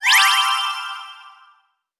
Magic_v4_wav.wav